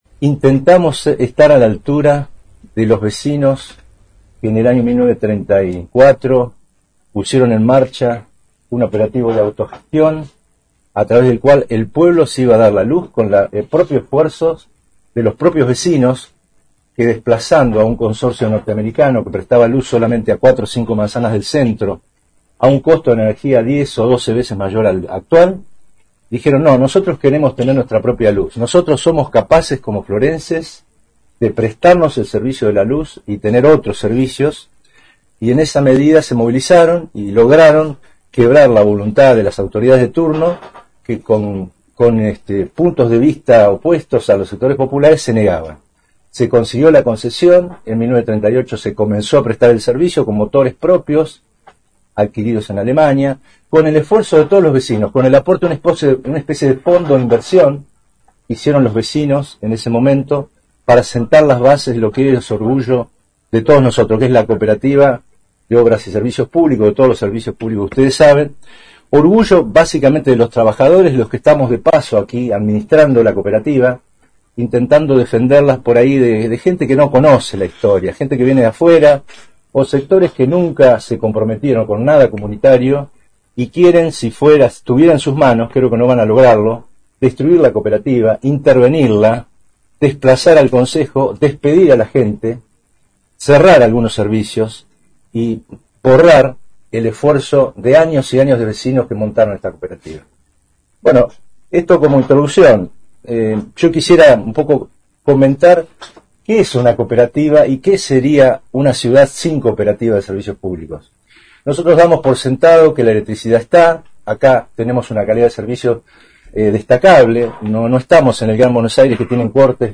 En el marco de una conferencia de prensa que tuvo lugar este jueves en la sala de reuniones de la Cooperativa Eléctrica Las Flores